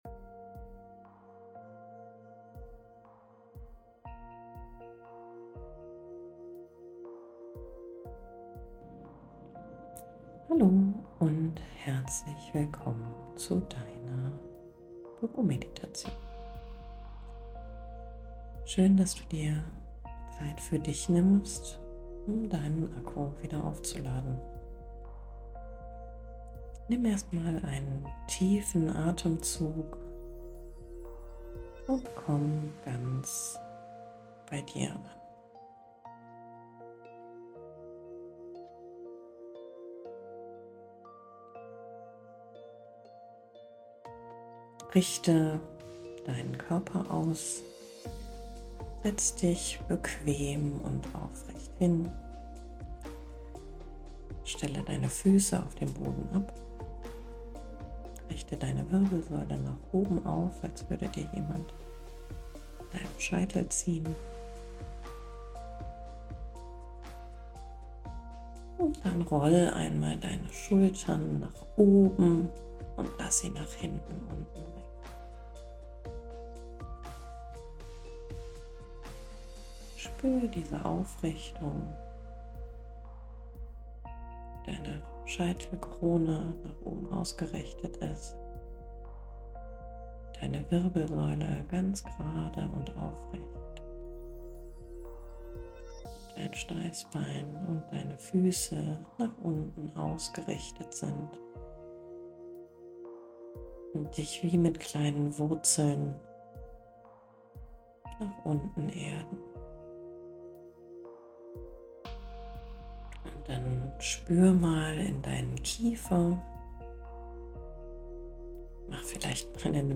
5 Minuten Meditation Resilienz Atemübung 4-7-8 Körperübungen Kale Ka Pa
✨ Hinweis mit Herz ✨ In den Meditationen, Atem- und Körperübungen spreche ich Sie bewusst mit "Du" an – für eine persönliche und entspannte Atmosphäre, die Ihnen hilft, ganz bei sich anzukommen.